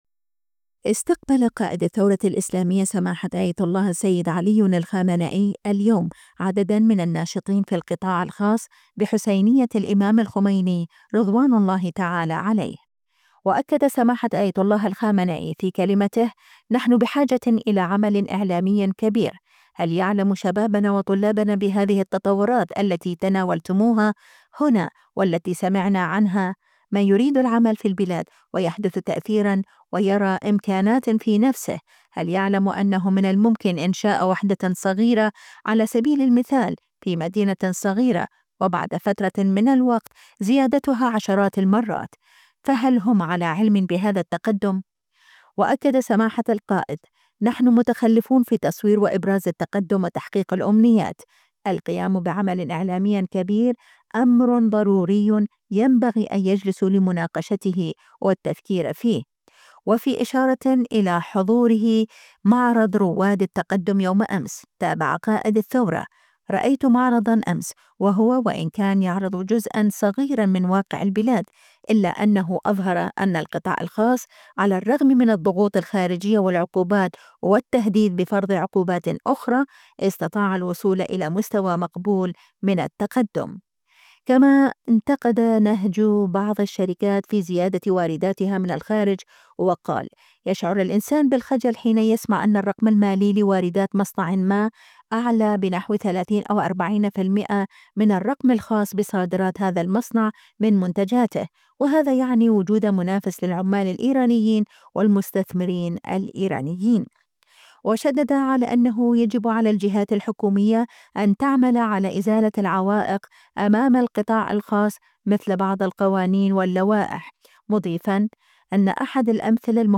استقبل قائد الثورة الاسلامية سماحة آية الله السيد علي الخامنئي اليوم عددا من الناشطين في القطاع الخاص بحسينية الإمام الخميني (رض).